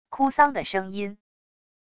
哭丧的声音wav音频